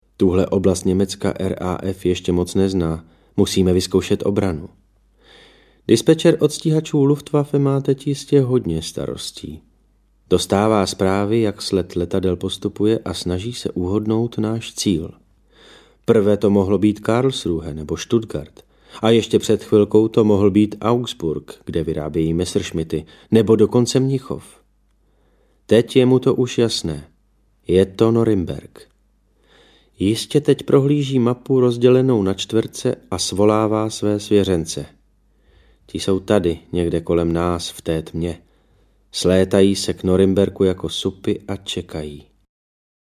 Nebeští jezdci audiokniha
Rozhlasová verze zčásti autobiografické knihy Filipa Jánského o letcích RAF (Royal Air Force) za 2. světové války ve Velké Británii.
Ukázka z knihy
• InterpretDavid Novotný